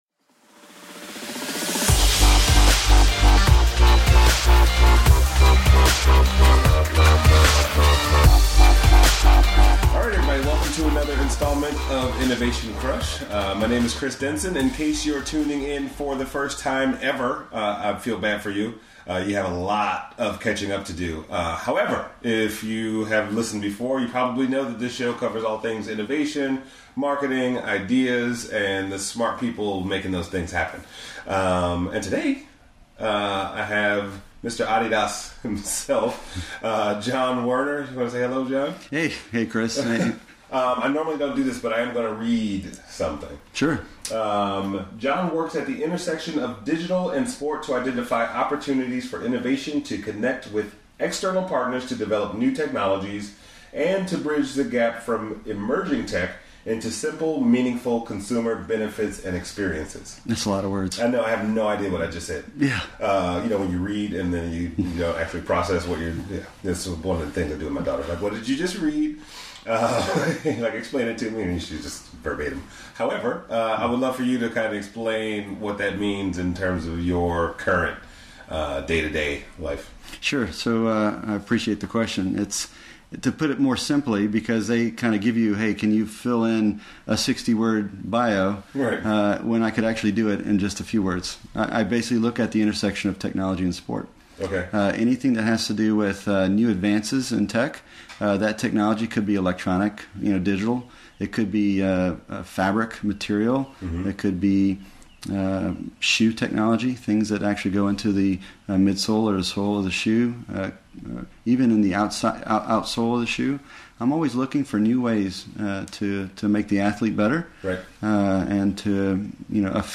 (recorded on location @ SXSW)